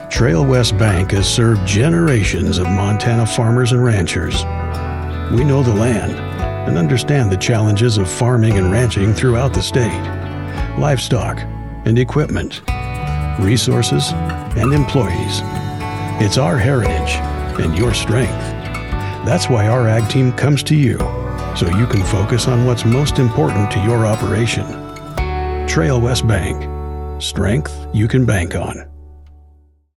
• Authentic and relatable — a real human presence, not a polished announcer veneer
• Gritty and grounded — forged in the mountains, with a modern Western edge
• Warm and trustworthy — the voice of a guide, not a salesman
Commercial Demo